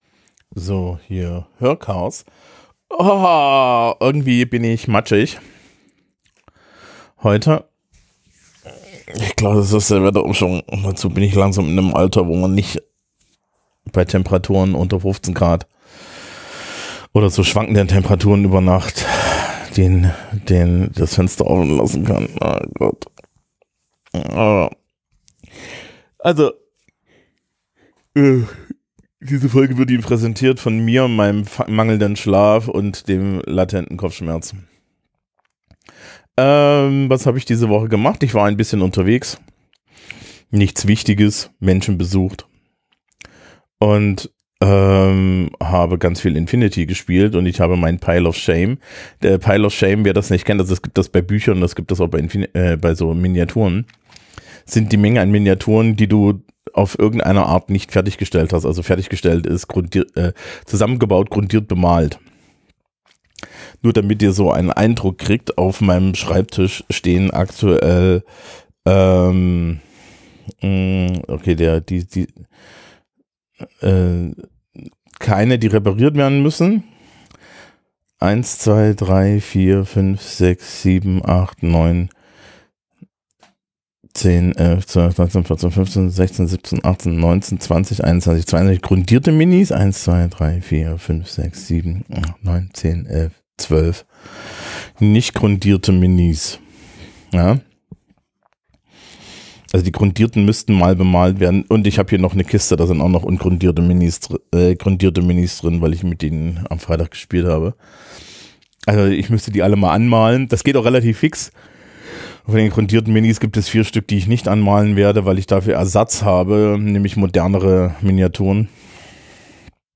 Ich entschuldige mich gleich, ich bin matschig.